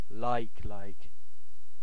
Ääntäminen
Ääntäminen UK Tuntematon aksentti: IPA : /ˈlaɪ̯k.laɪ̯k/ Haettu sana löytyi näillä lähdekielillä: englanti Käännöksiä ei löytynyt valitulle kohdekielelle.